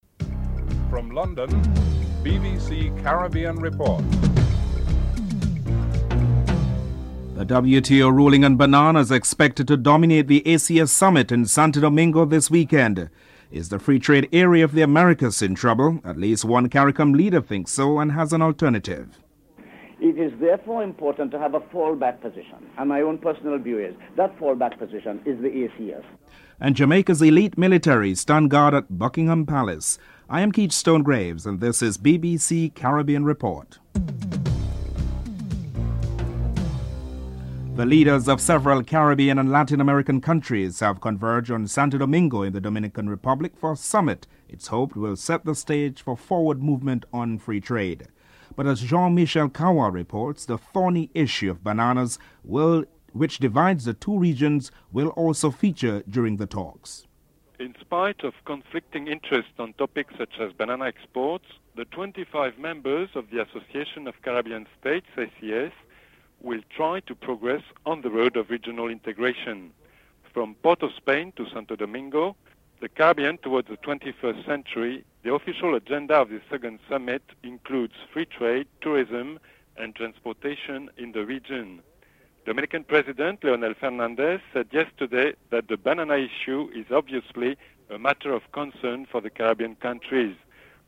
3. Secretary General of the Organization of American States (OAS) Simón Molina Duarte is warning of political and economic problems for the Caribbean region if a solution is not found for the current impasse in the banana industry.